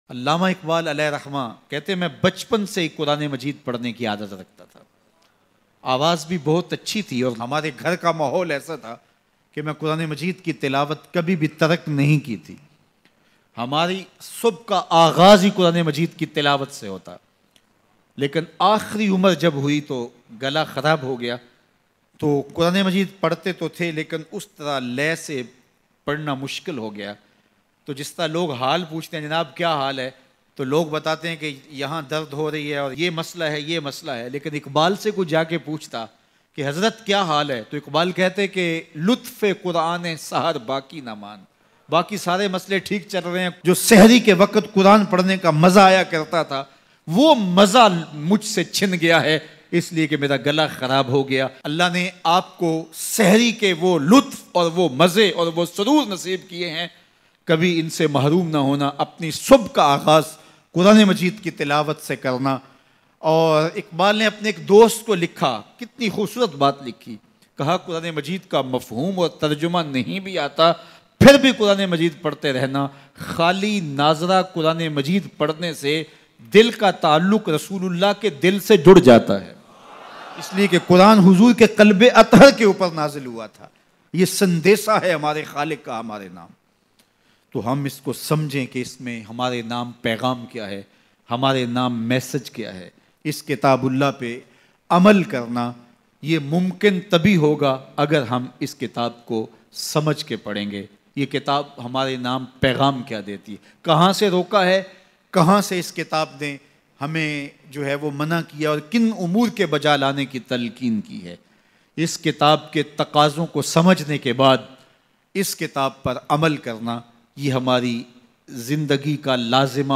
QURAN Se Mot Talash Krne Walo Bayan